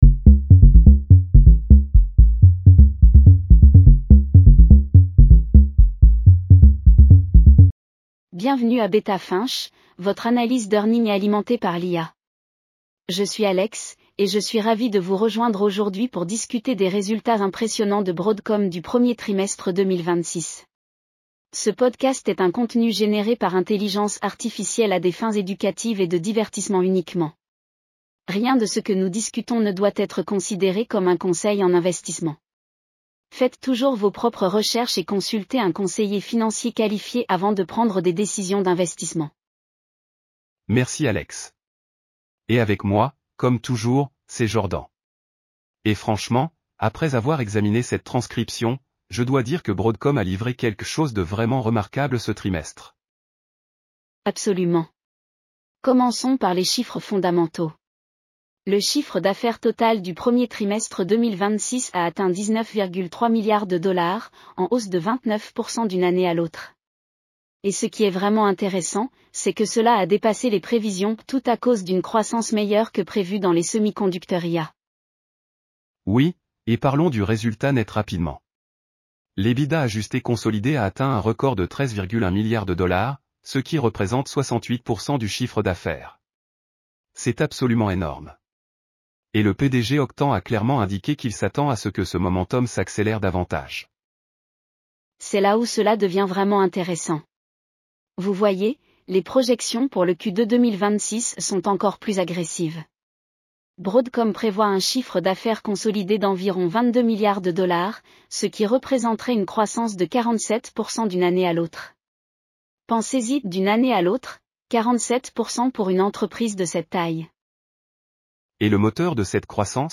AI-powered earnings call analysis for Broadcom (AVGO) Q1 2026 in Français. Listen to quarterly financial insights and key takeaways.